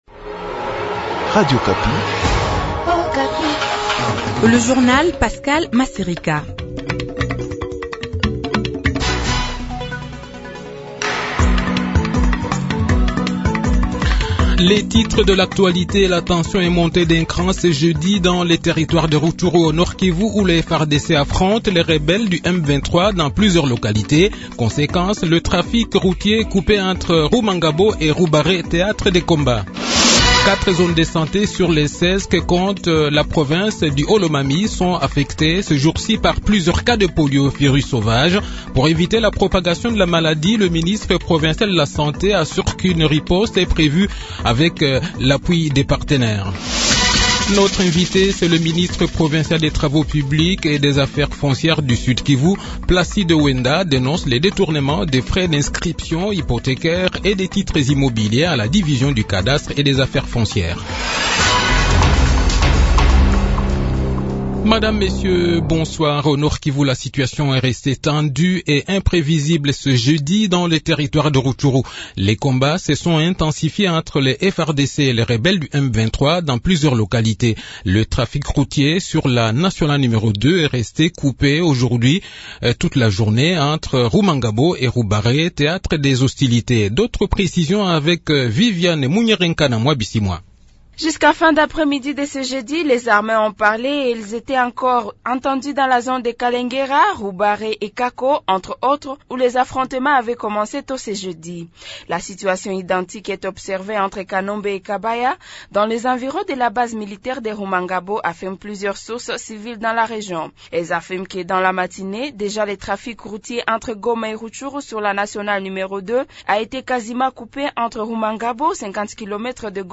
Le journal de 18 h, 27 octobre 2022